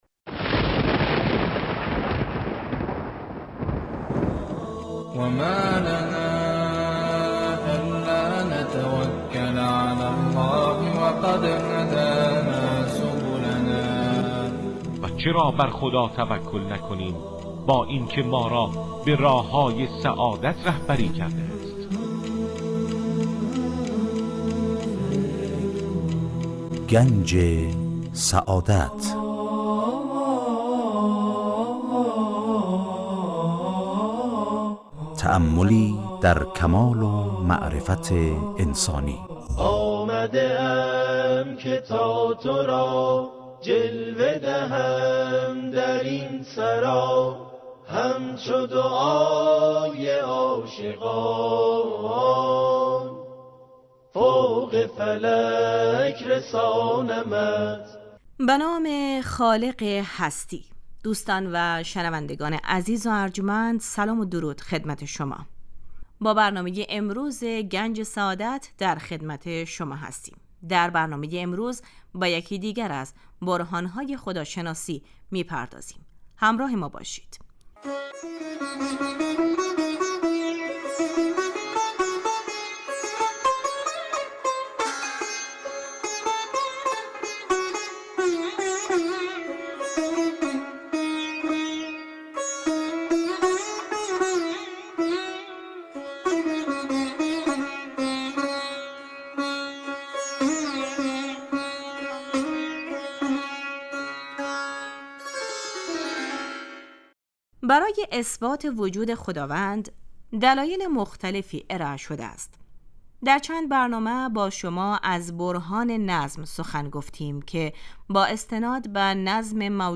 در این برنامه سعی می کنیم موضوعاتی همچون ؛ آفرینش ، یکتاپرستی و آثار و فواید آن، همچنین فلسفه و اهداف ظهور پیامبران را از منظر اسلام مورد بررسی قرار می دهیم. موضوعاتی نظیر عدل خداوند، معاد و امامت از دیگر مباحثی است که در این مجموعه به آنها پرداخته می شود این برنامه هر روز به جزء جمعه ها حوالی ساعت 12:35 از رادیودری پخش می شود.